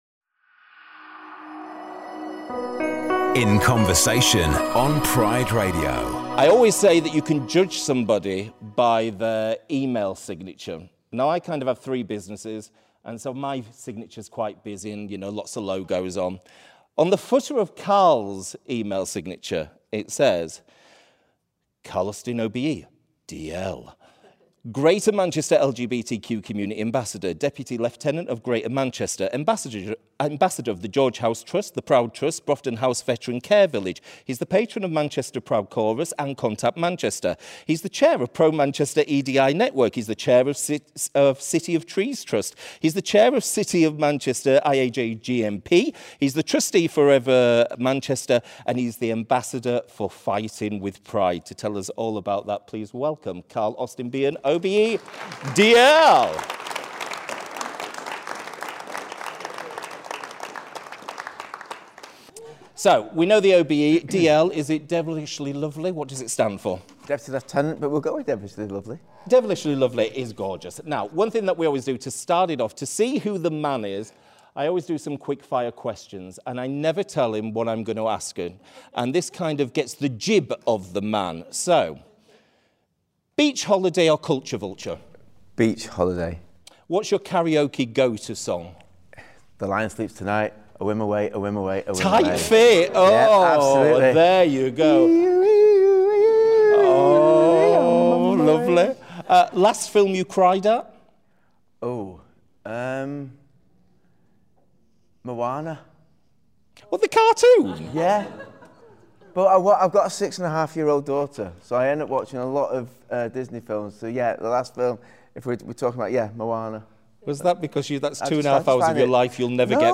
An Audience with CARL AUSTIN-Behan OBE DL Recorded at HMS Calliope on Thursday 21st August 2025 To commemorate the 25th anniversary since the lifting of the ban on LGBTQ+ people serving in the armed forces, Out North East is proud to welcome Dr Carl Austin-Behan OBE DL to our Summer of Pride.